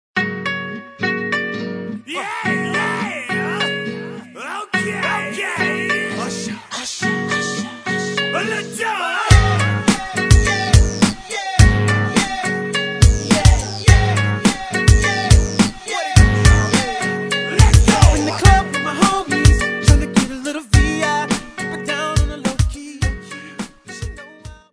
A short mobile version